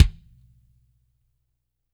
-DRY NS 12-L.wav